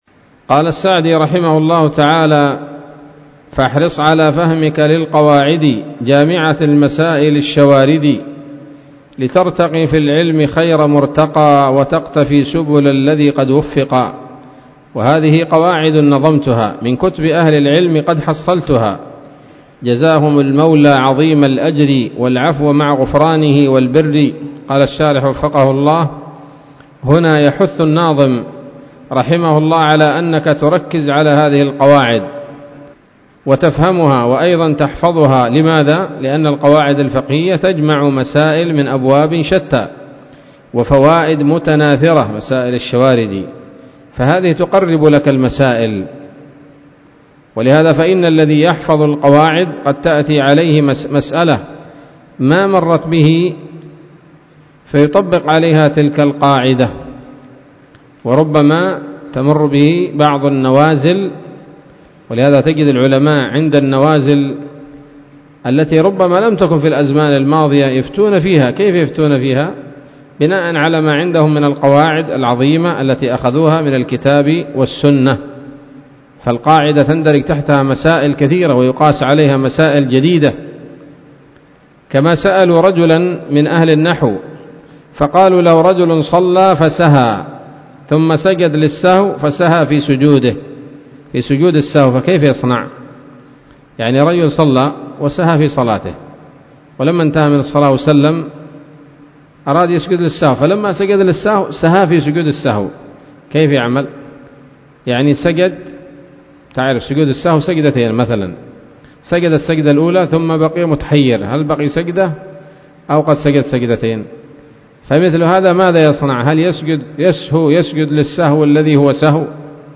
الدرس الثامن من الحلل البهية في شرح منظومة القواعد الفقهية